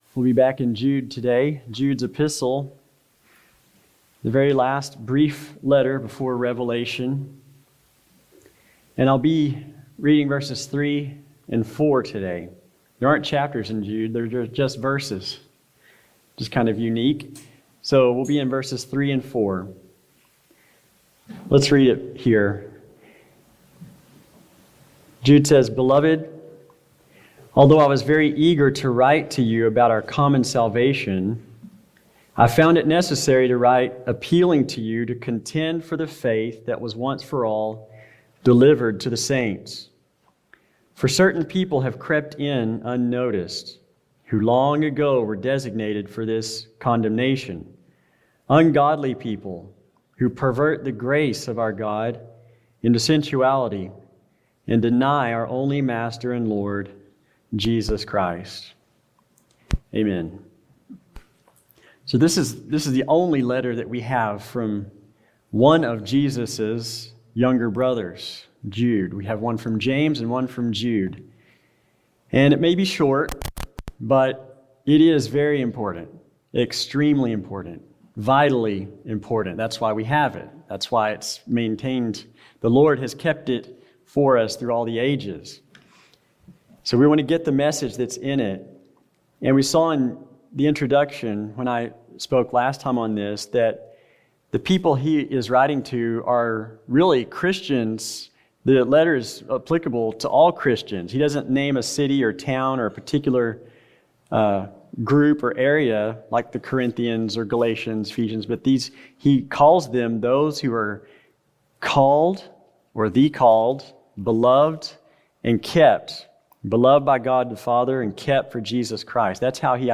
Contend for the Faith | SermonAudio Broadcaster is Live View the Live Stream Share this sermon Disabled by adblocker Copy URL Copied!